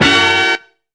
WILD HIT.wav